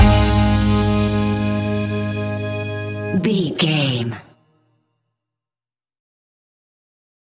Uplifting
Ionian/Major
A♭
pop rock
fun
energetic
acoustic guitars
drums
bass guitar
electric guitar
piano
organ